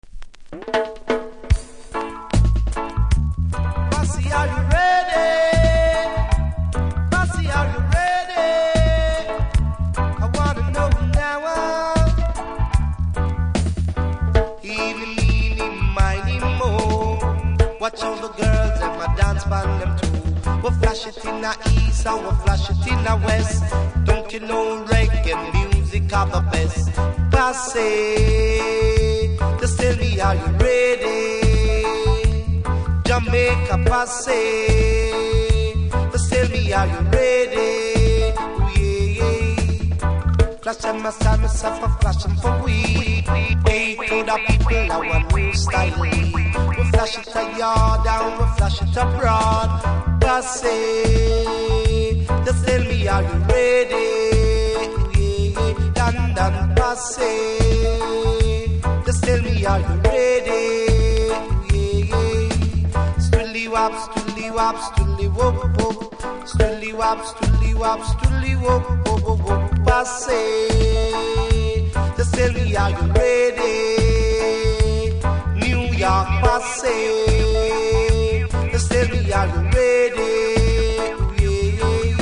プレス起因の凸がありノイズ拾いますので試聴で確認下さい。